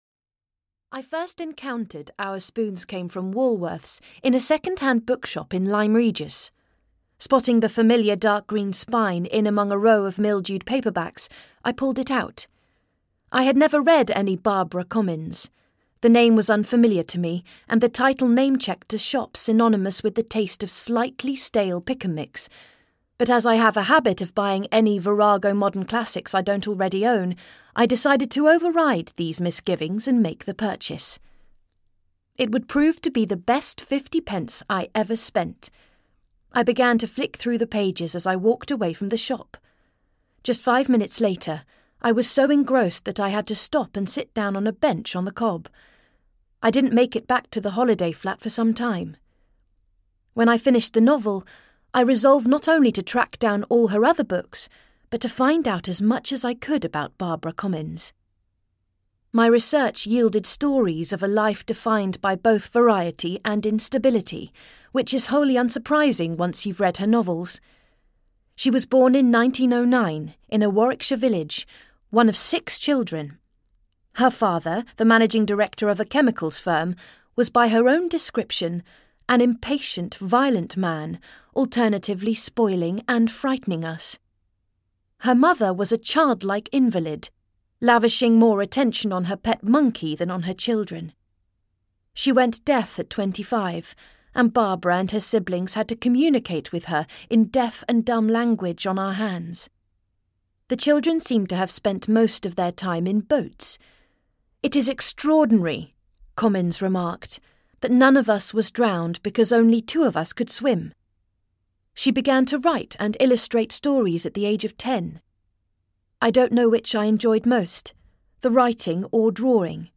Our Spoons Came from Woolworths (EN) audiokniha
Ukázka z knihy